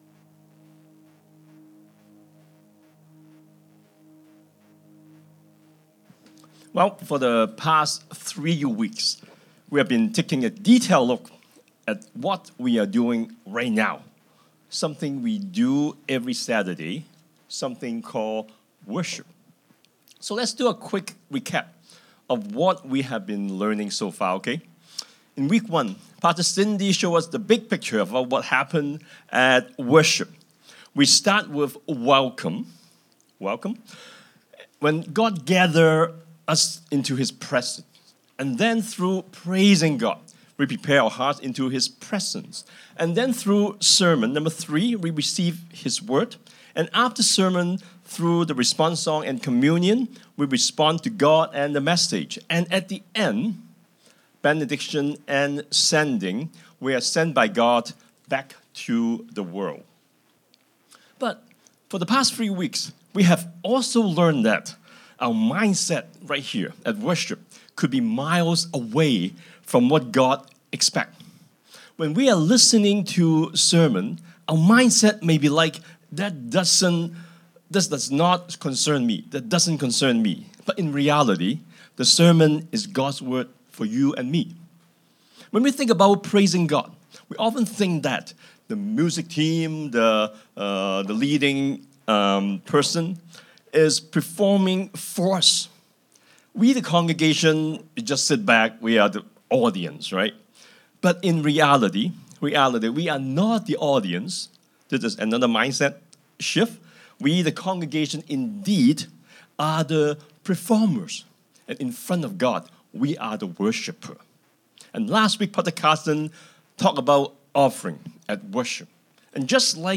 Sermons | Koinonia Evangelical Church | Live Different!